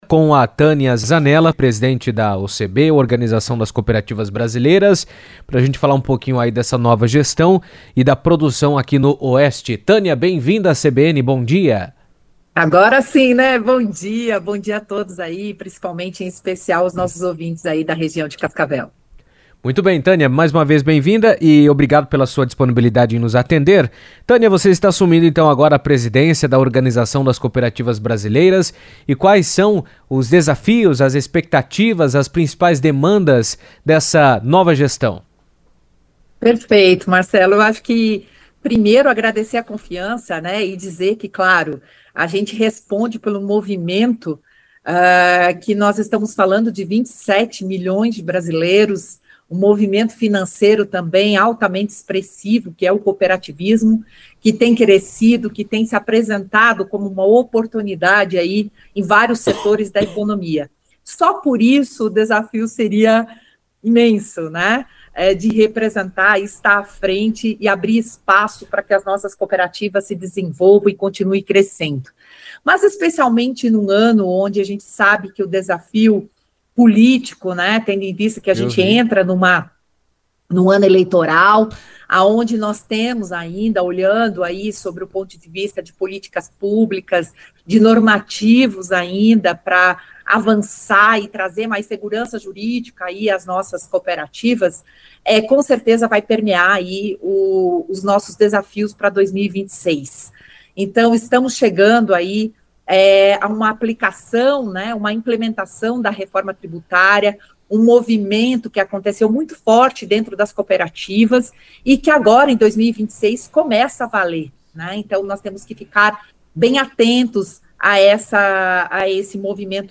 Em entrevista à CBN, ela ressaltou a importância das cooperativas da região para o fortalecimento da economia, afirmando que o setor seguirá como um dos pilares do desenvolvimento sustentável e da geração de emprego e renda no Brasil.